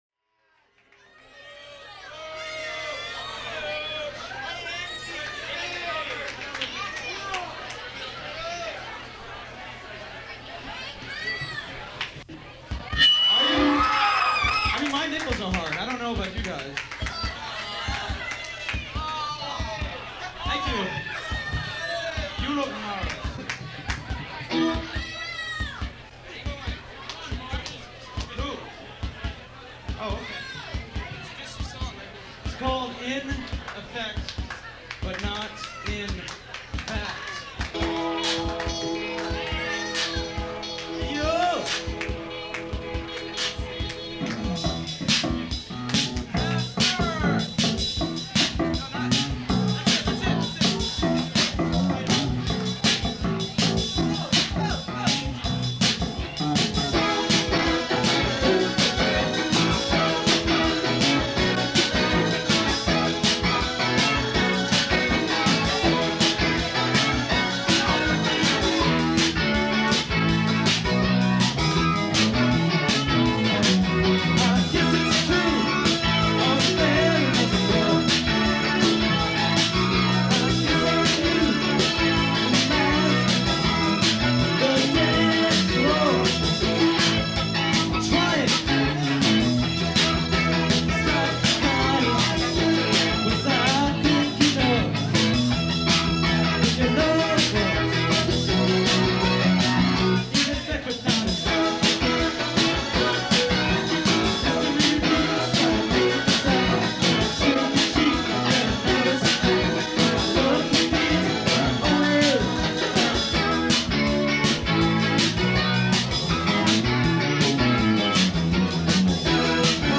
In Effect But Not In Fact LIVE | In Effect But Not In Fact